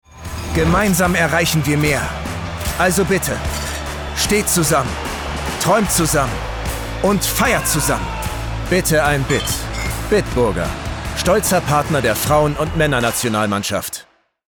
dunkel, sonor, souverän, markant, sehr variabel
Berlinerisch
Commercial (Werbung)